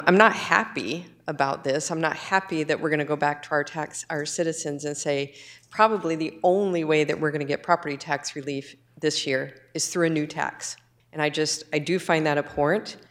Sen. Taffy Howard of Rapid City voted yes — but made clear she wasn’t happy about it.